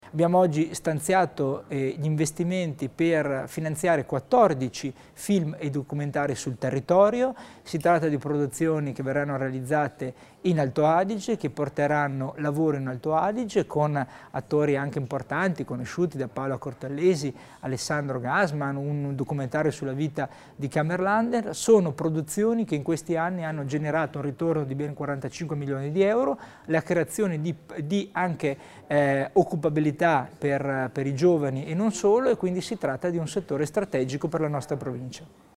Il Vicepresidente Tommasini elenca i progetti di sostegno alle produzioni cinematografiche